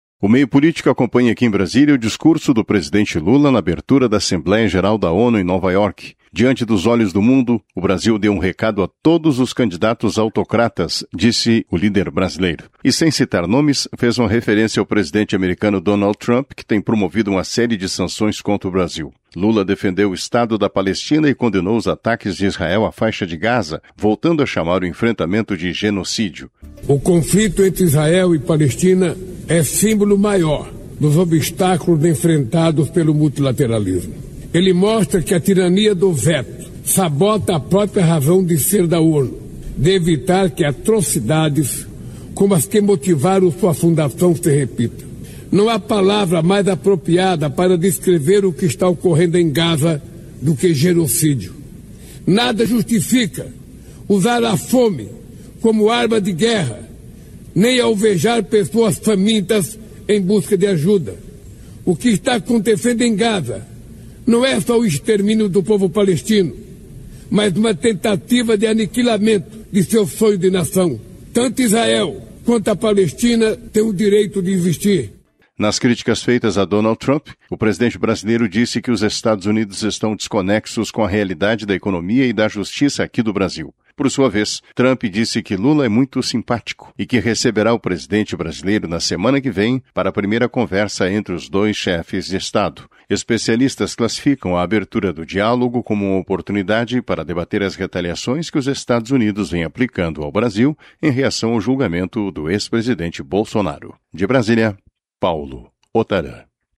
Lula discursa na ONU sobre matança na Faixa de Gaza e sobre sanções americanas ao Brasil
Lula-discursa-na-ONU-sobre-matanca-na-Faixa-de-Gaza-e-sobre-sancoes-americanas-ao-Brasil.mp3